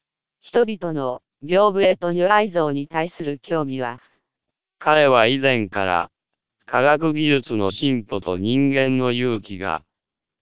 Experts haven't found significant difference in sounding between SPR 1200 and MELPe 1200 vocoders.
You can play and listen short samples of the source speech as well as the speech processed by these vocoders for any of 20 languages, using links in the table below.